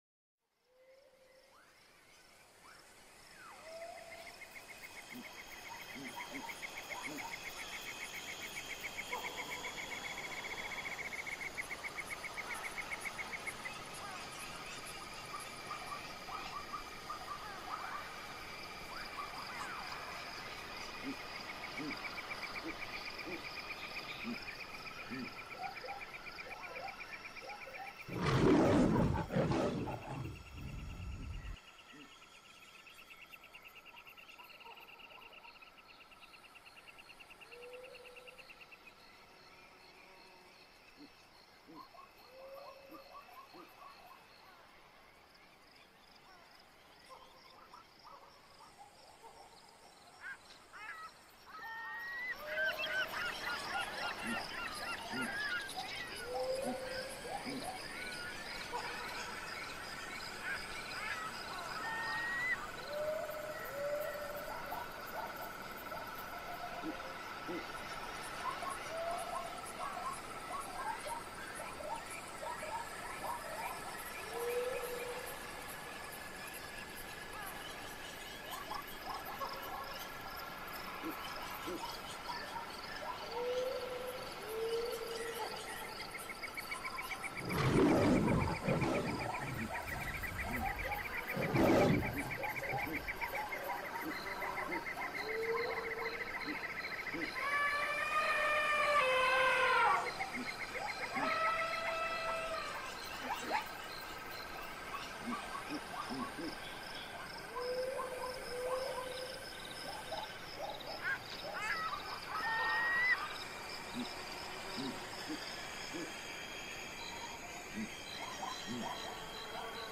Sonido-de-animales-salvajes-de-África.mp3